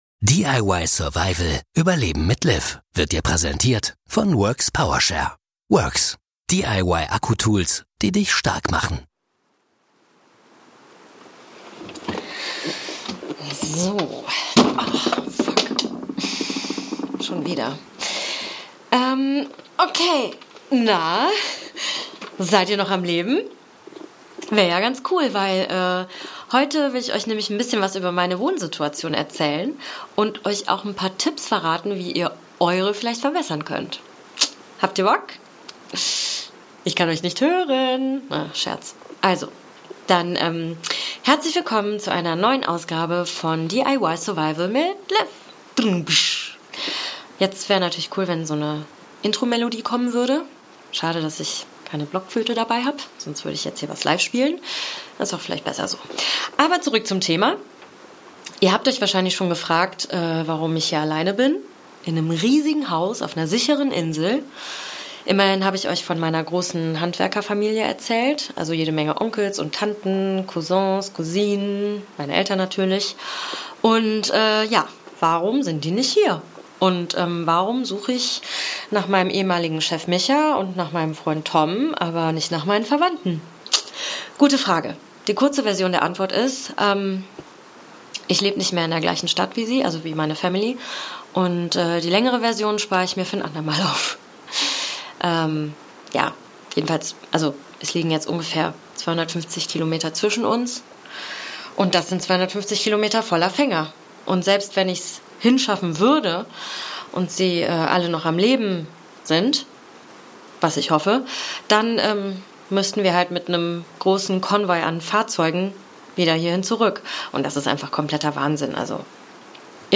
Fiction-Podcast